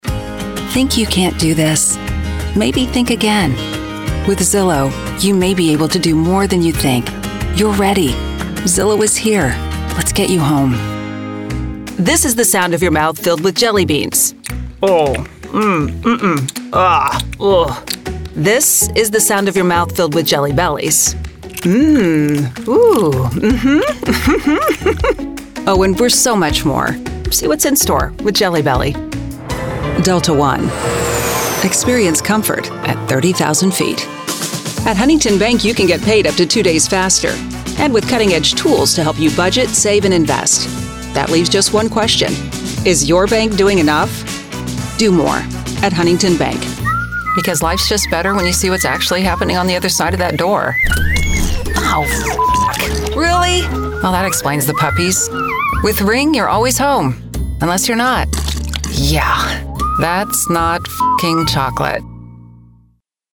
a warm, authentic, familiar, credible, friendly, energetic sound to commercials, promos, corporate videos, e-learning, audiobooks and more